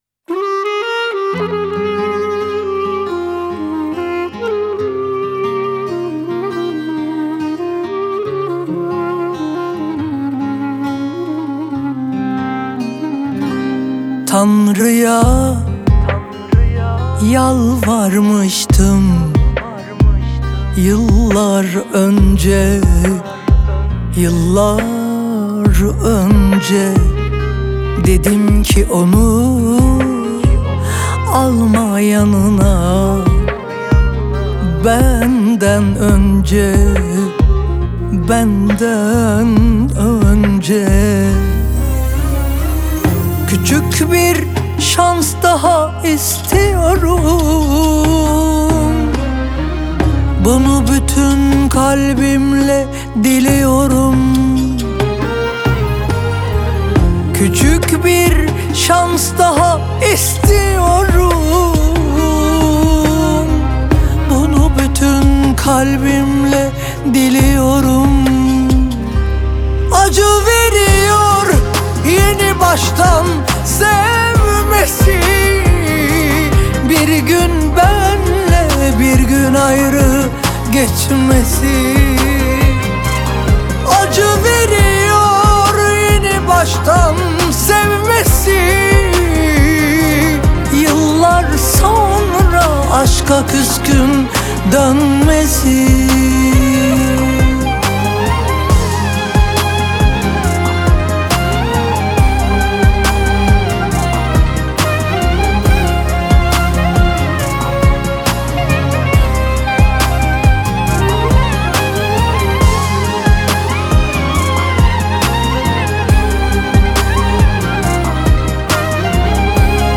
آهنگ ترکیه ای آهنگ غمگین ترکیه ای آهنگ هیت ترکیه ای